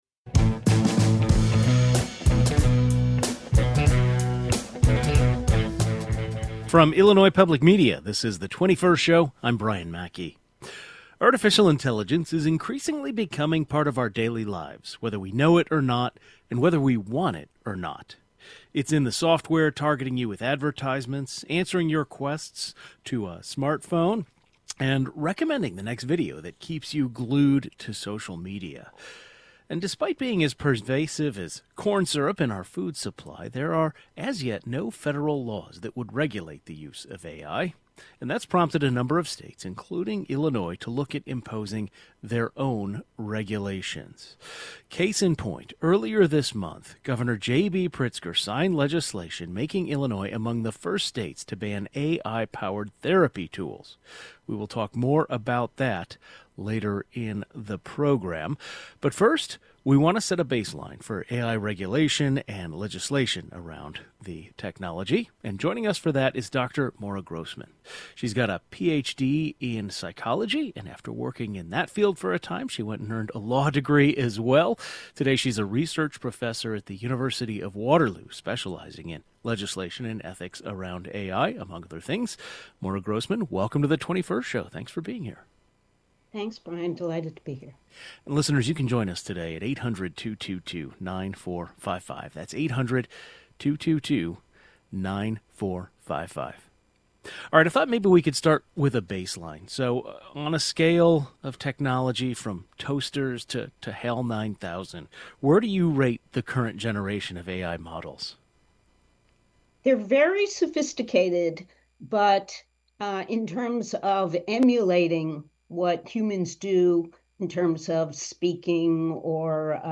That’s prompted a number of states, including Illinois, to look at imposing their own regulations. A legal expert with insight into AI research joins the program to explore potential legalities around this phenomenon.